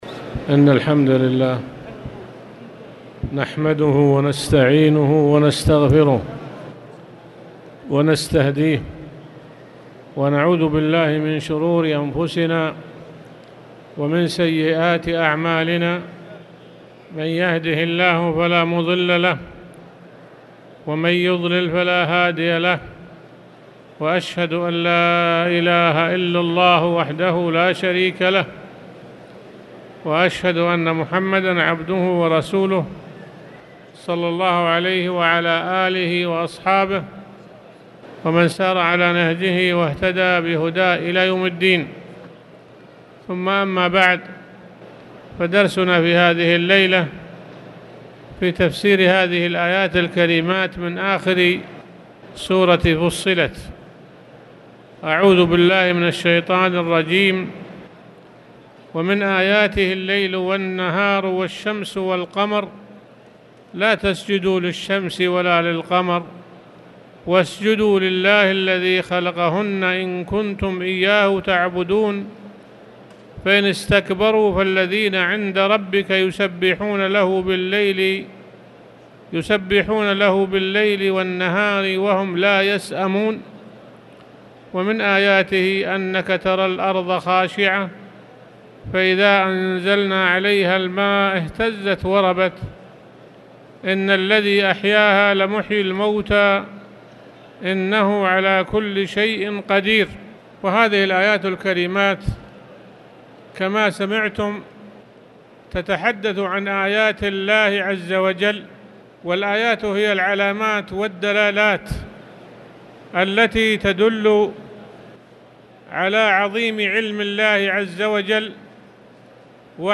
تاريخ النشر ١٩ شعبان ١٤٣٨ هـ المكان: المسجد الحرام الشيخ